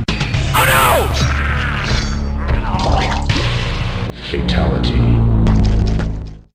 Added a sword combat soundpack for bashit.
lose.ogg